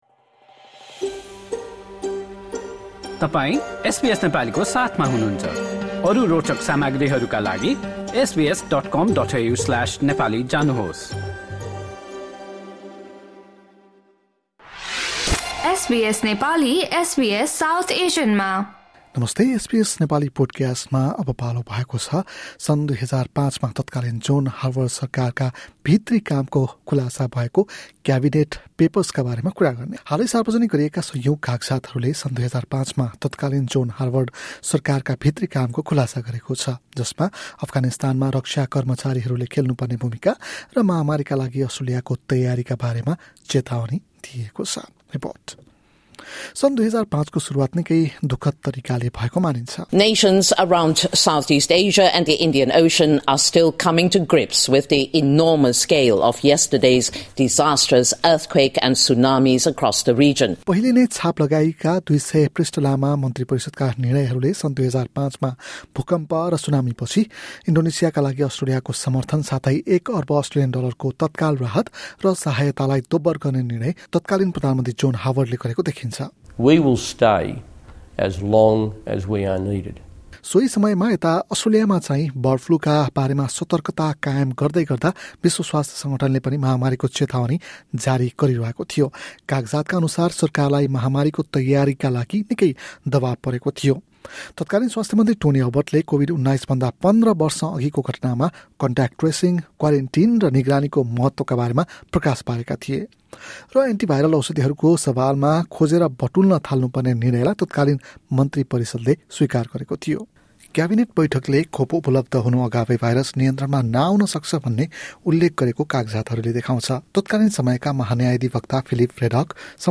हालै सार्वजनिक गरिएका कागजातहरूले सन् २००५ मा तत्कालीन हावर्ड सरकारका भित्री कामको खुलासा गरेको छ, जसमा अफगानिस्तानमा रक्षा कर्मचारीहरूले खेल्नु पर्ने भूमिका र महामारीका लागि अस्ट्रेलियाको तयारीका बारेमा चेतावनी दिइएको छ। एक रिपोर्ट।